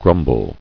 [grum·ble]